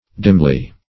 Dimly \Dim"ly\, adv.